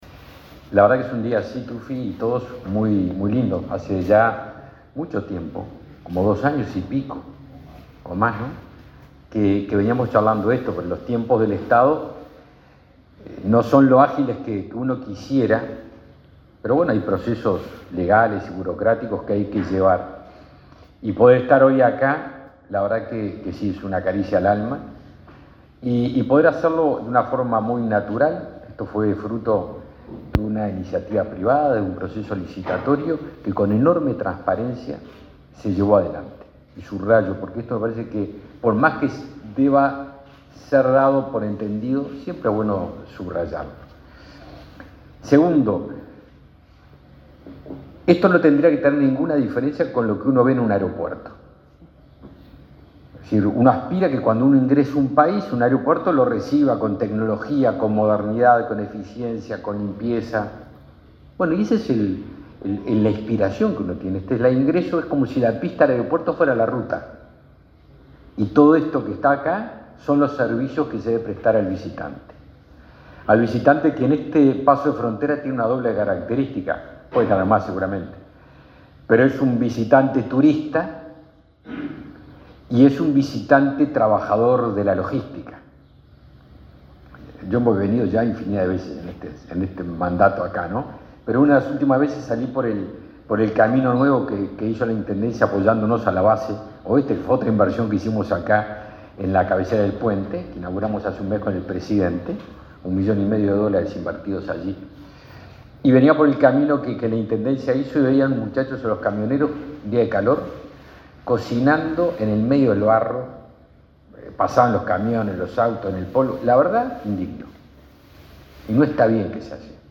Palabras del ministro de Defensa Nacional, Javier García